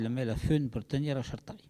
Maraîchin